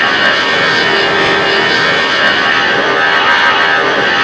cfm-buzz.wav